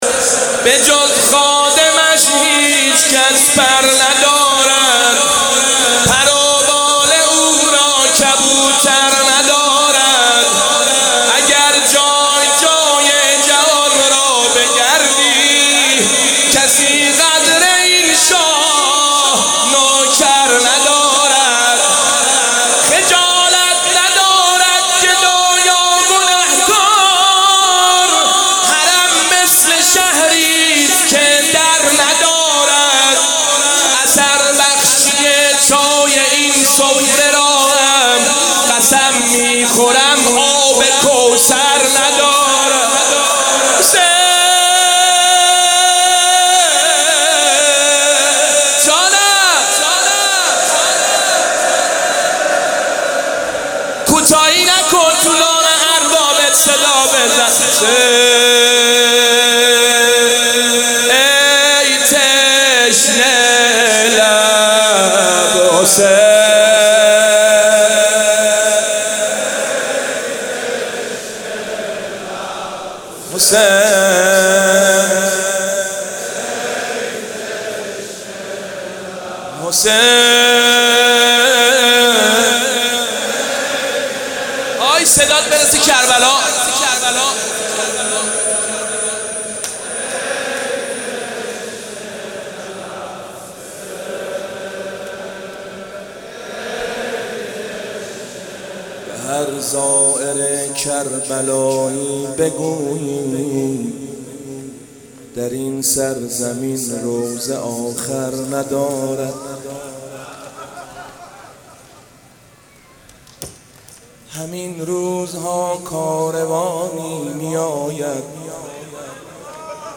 مراسم شب دوم محرم الحرام سال 1395